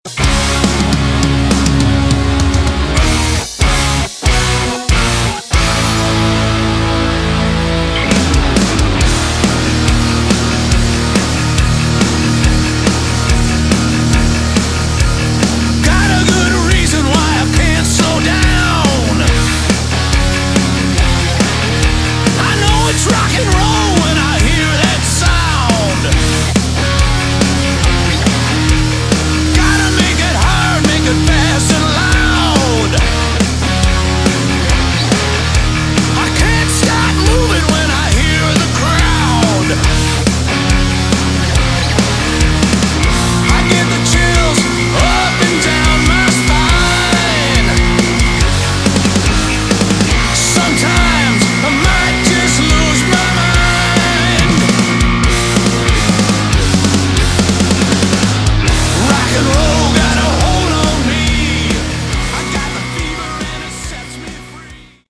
Category: Melodic Metal
guitar, vocals
bass
drums